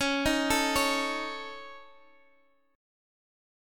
Dbsus2#5 Chord